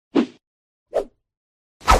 Whoosh
Whoosh is a free sfx sound effect available for download in MP3 format.
014_whoosh.mp3